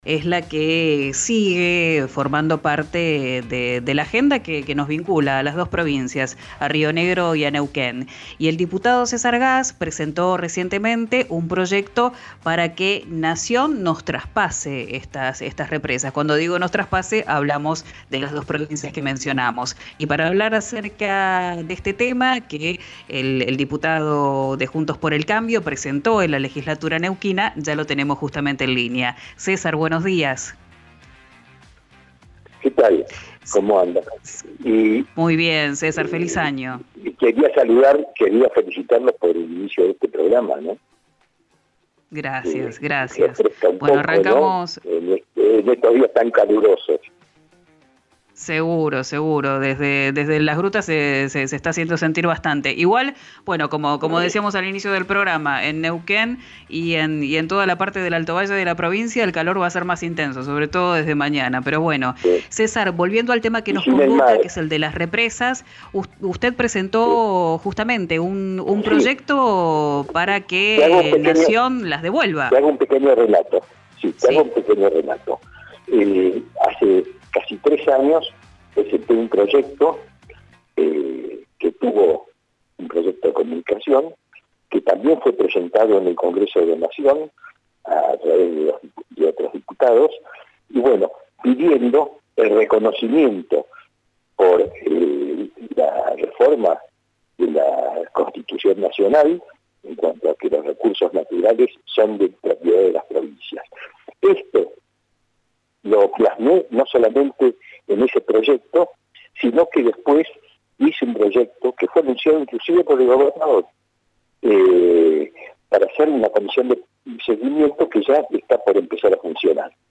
El diputado César Gass manifestó su enojo en "Quién dijo verano", el nuevo programa de RÍO NEGRO RADIO. Sostuvo que ENARSA se quiere quedar con los recursos de la provincia.
Escuchá al diputado de Neuquén por la UCR, César Gass, en “Quién dijo verano”, por RÍO NEGRO RADIO.